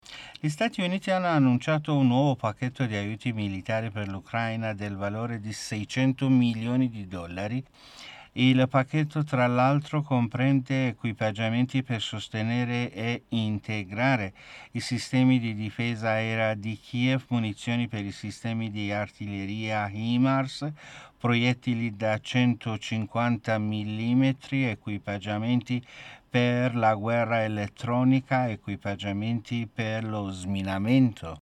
Notiziario / mondo